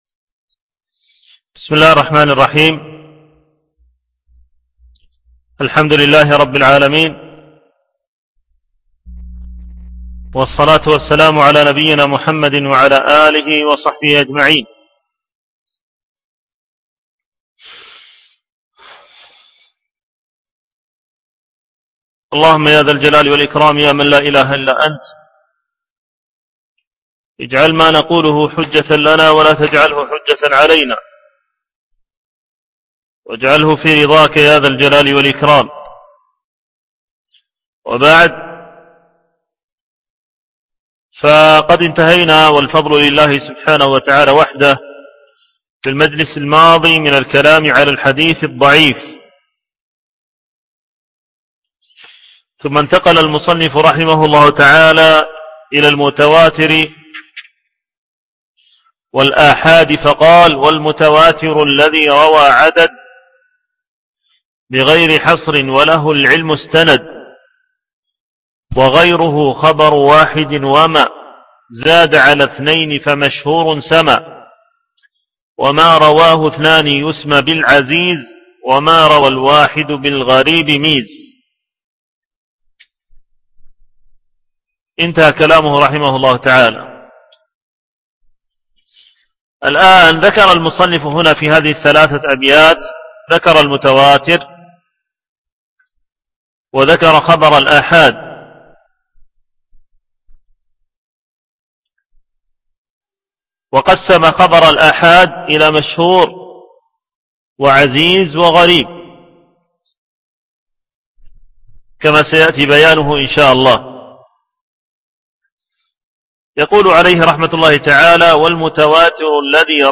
شرح طرفة الطرف في مصطلح من سلف - الدرس السادس